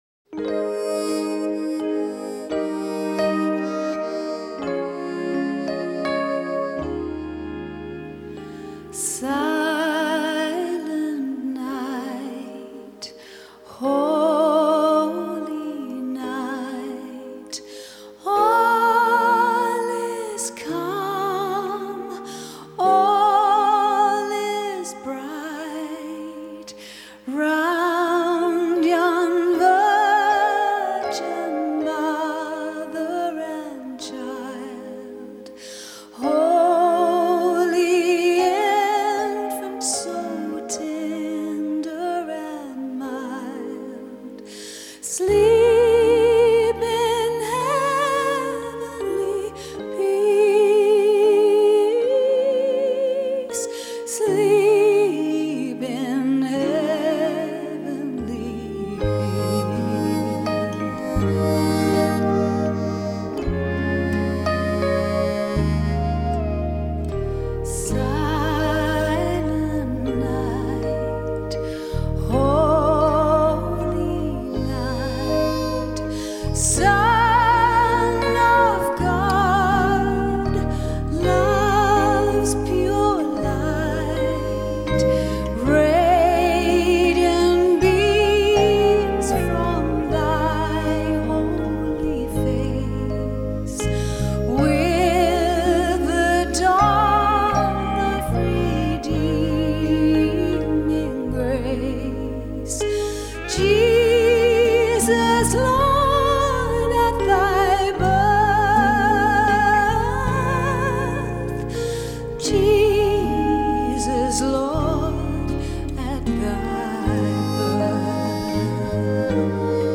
Mp3 | VBR kbps | 44,1kH | Stereo | Pop | 53 Mb